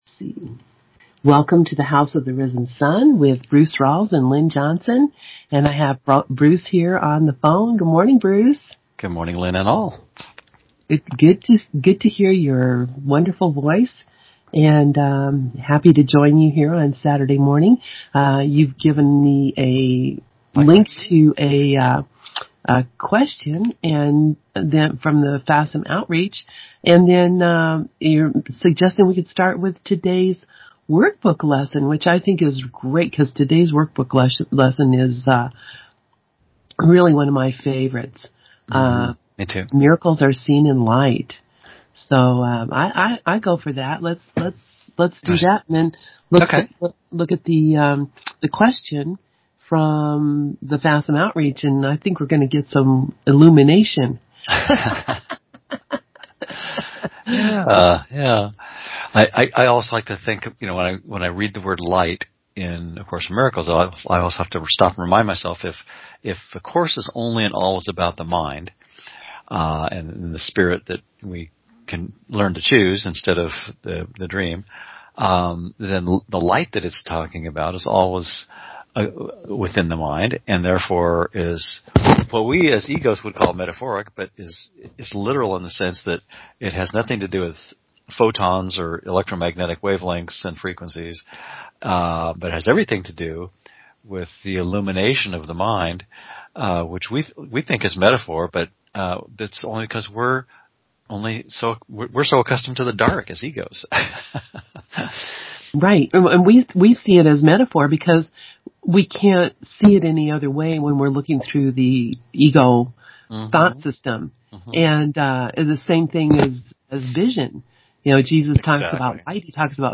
In their weekly live online series Saturday mornings on ACIM Gather, they typically read together from FACIM’s FAQs (or other topical material of interest to students of A Course in Miracles) and add commentary inspired by what they have found helpful during the week.
(This audio program originally aired on ACIM Gather on April 1, 2017.)